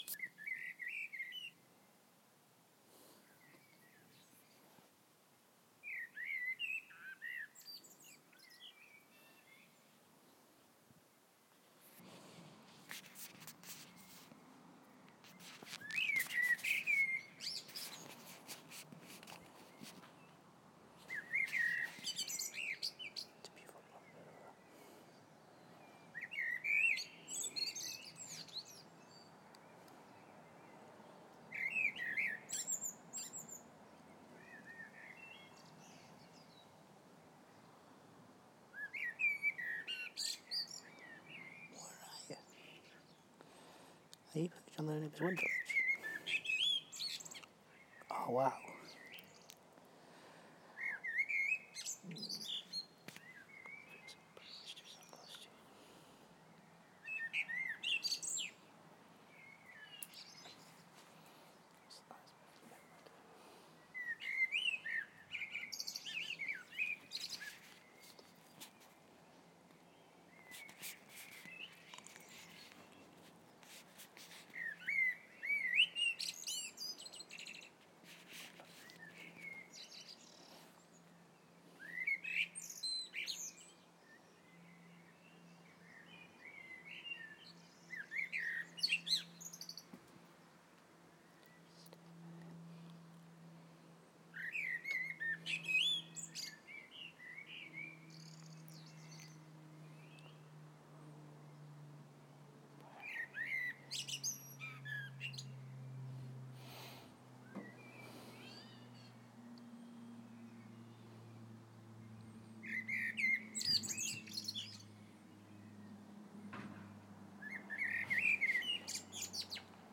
Very close recording of a black bird. Ignore the take away delivery at the end.